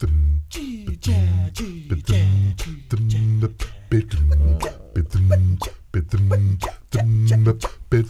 ACCAPELLA 7C.wav